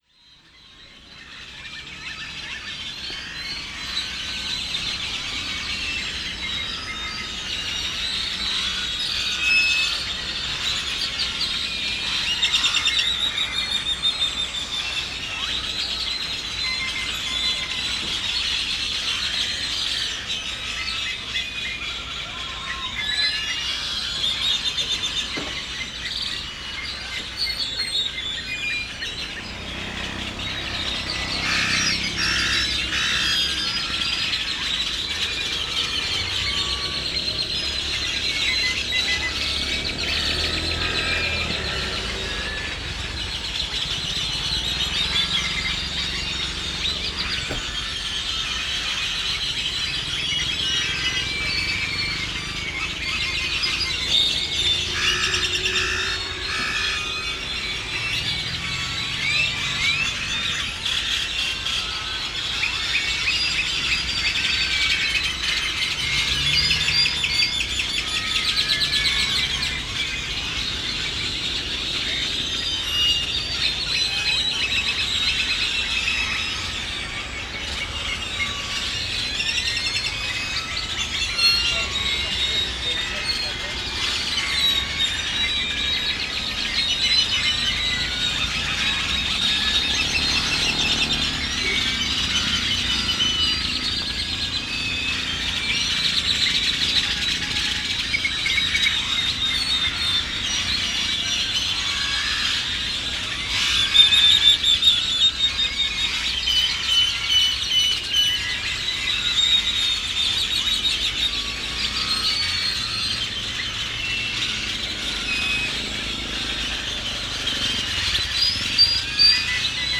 Orquesta de zanates
Es el tiempo en que se abre el atardecer para dejar entrar en largos minutos la noche, cada graznido de la orquesta convierte en recital único e irrepetible cada día, sin tregua, que ensordece los sonidos cotidianos de motores, diálogos y pasos de su alrededor.
archivosonoro-orquesta-de-zanates.mp3